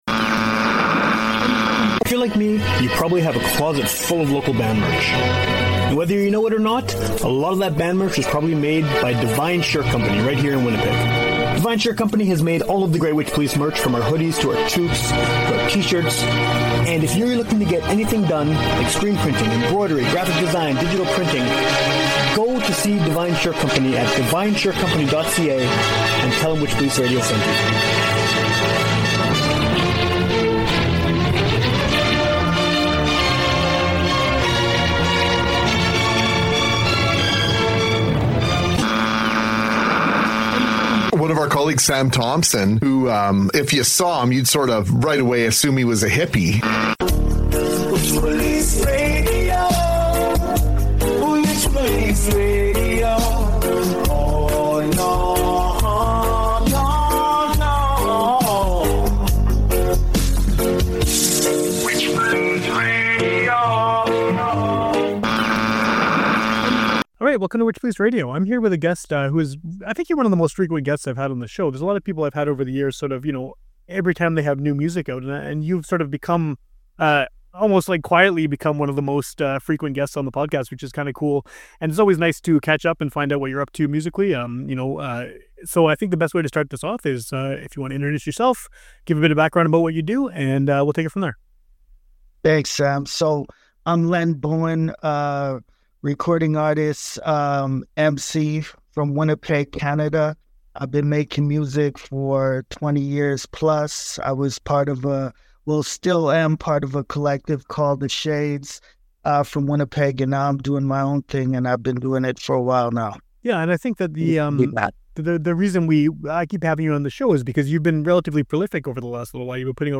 Now with repaired audio!
interview
(Note: there was some weird echo/distortion on one half of of the conversation, so if you downloaded this when it first came out and haven't listened yet, go with this version instead.